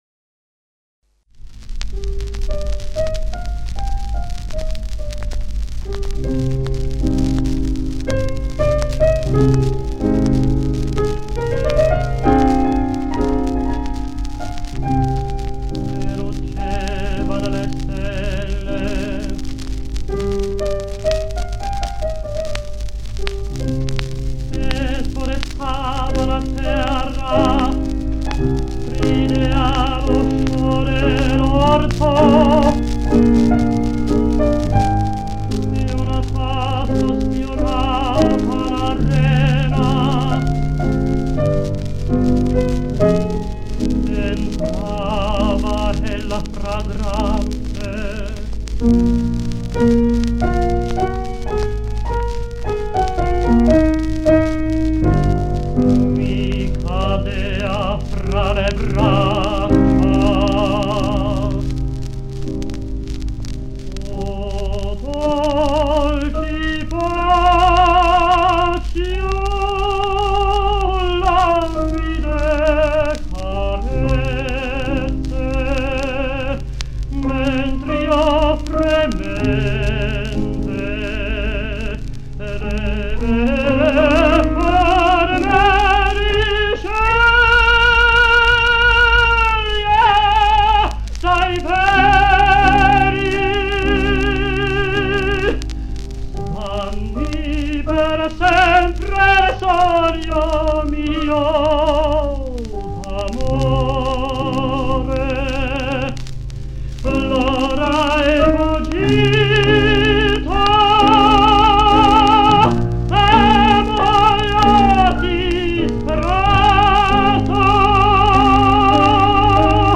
Aufnahmen aus den 1950er und frühen 1960er Jahren
Oper:
tosca_cavaradossi_arie.mp3